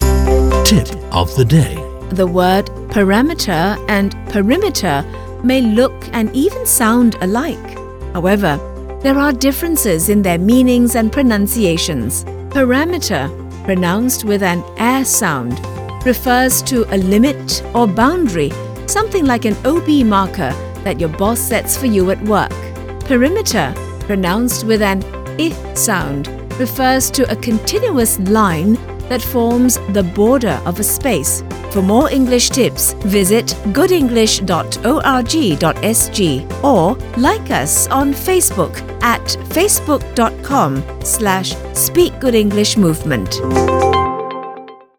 At one glance, the word “parameter” and “perimeter” may look and even sound alike.
“Parameter”, pronounced with a short “a” sound, refers to a limit or boundary, something like an OB marker that your boss sets for you at work.
On the other hand, “perimeter”, pronounced with a short “i” sound followed by a short and controlled “e” sound, refers to a continuous line that forms the border of a space.